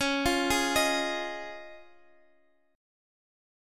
C#add9 chord